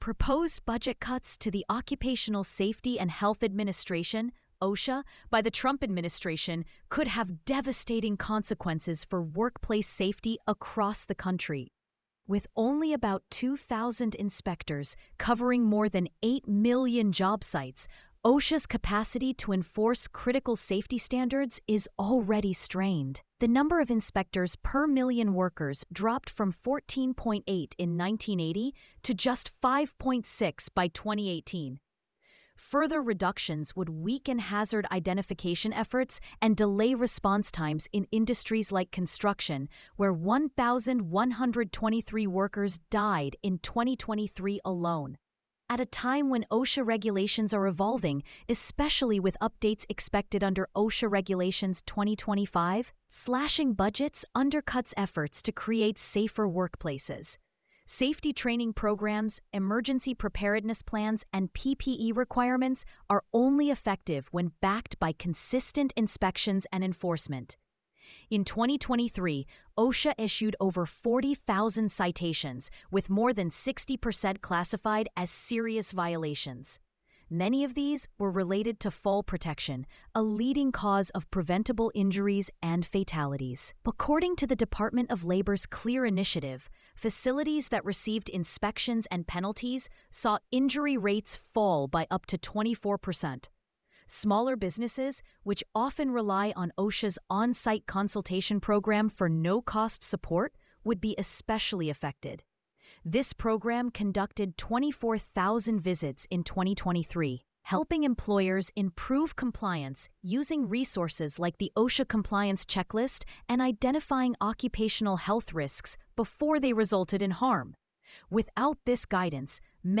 speech
sage_gpt-4o-mini-tts_1x_2025-06-09T16_20_02-947Z.wav